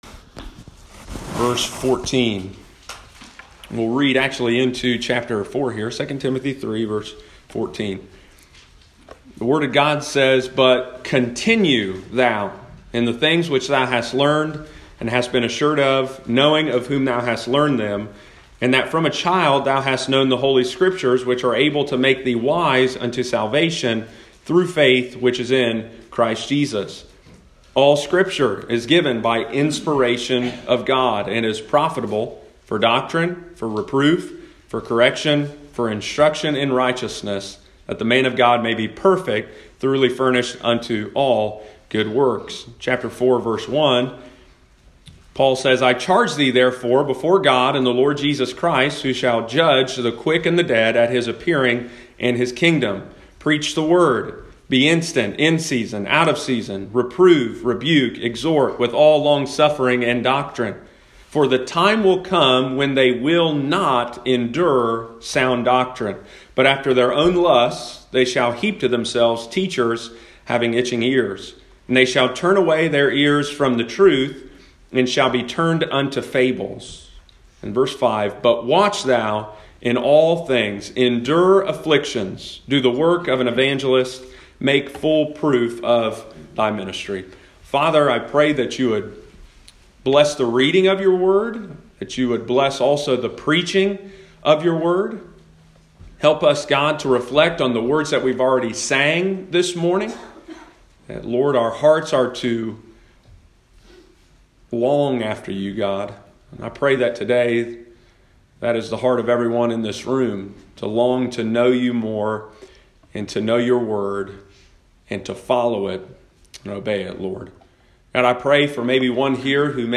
sermon series
Sunday morning, January 12, 2020.